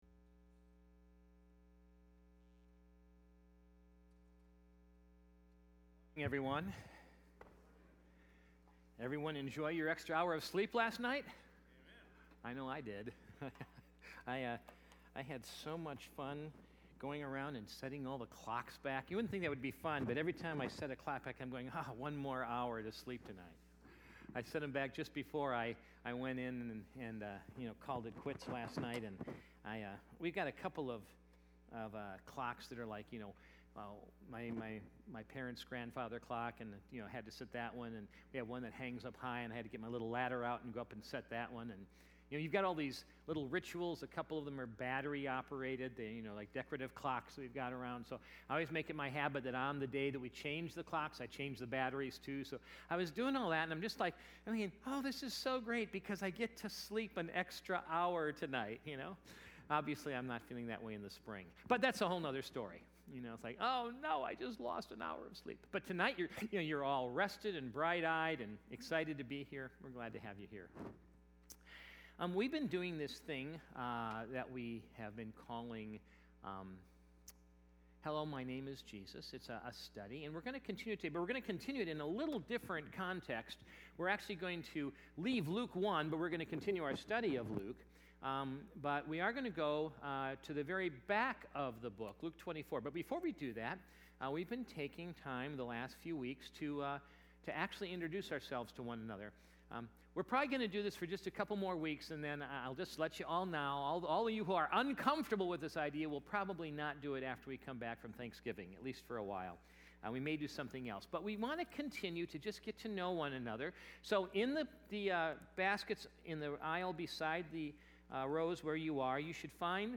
Sunday Morning Message
Sun_AM_Sermon_11_3_19.mp3